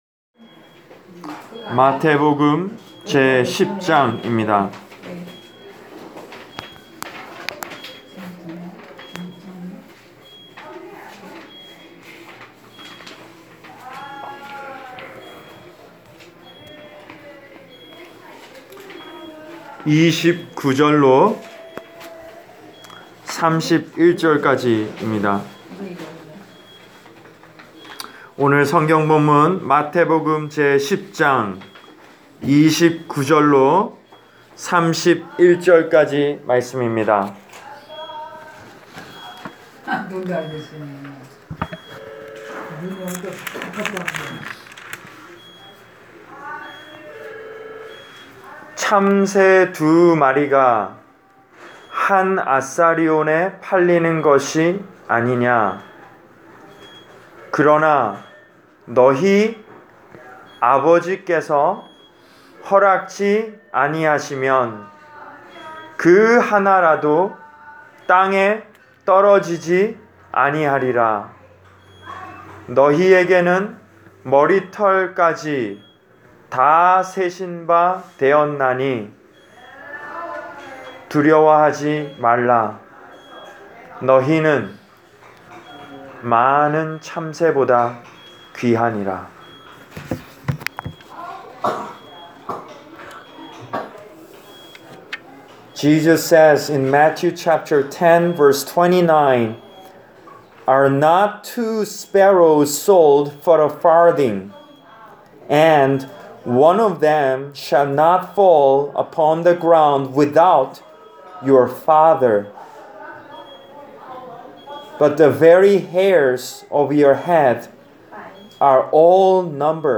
Preached for: Country Arch Care Center, Pittstown, N.J.